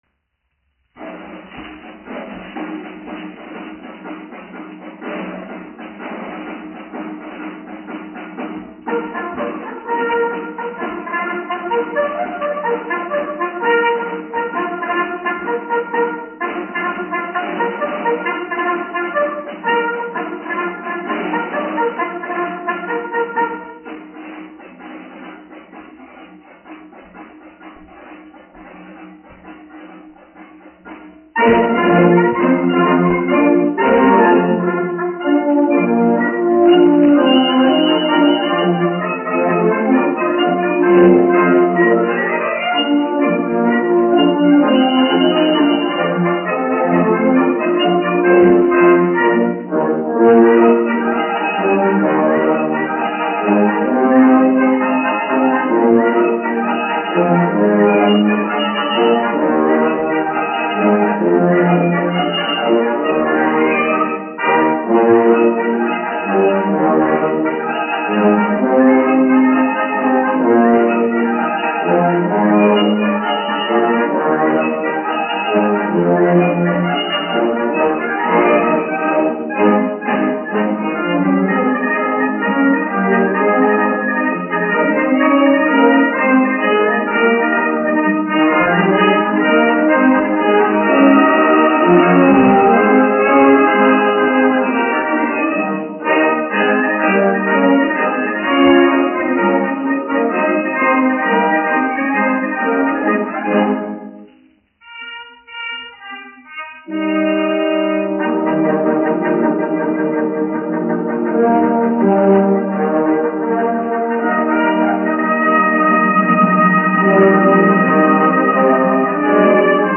1 skpl. : analogs, 78 apgr/min, mono ; 25 cm
Pūtēju orķestra mūzika
Skaņuplate
Latvijas vēsturiskie šellaka skaņuplašu ieraksti (Kolekcija)